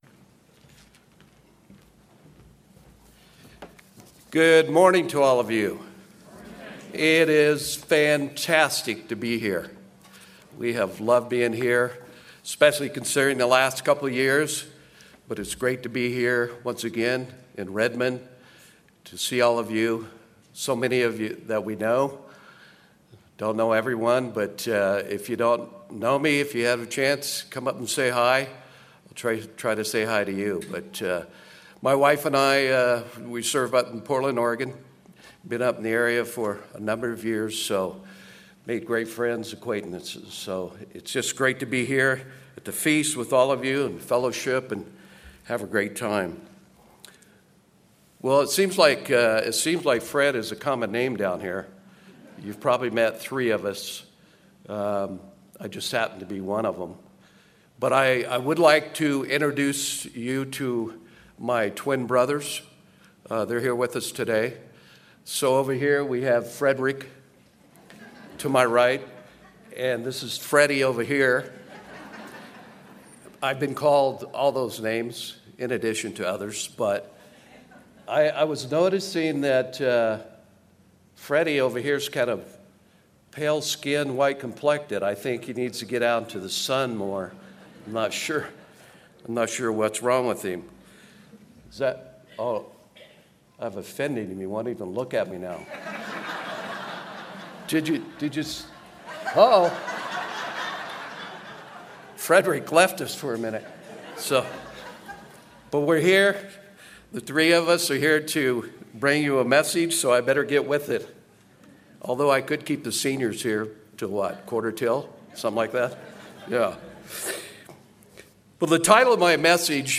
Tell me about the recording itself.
This sermon was given at the Bend-Redmond, Oregon 2022 Feast site.